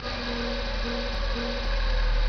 mixergrinder.mp3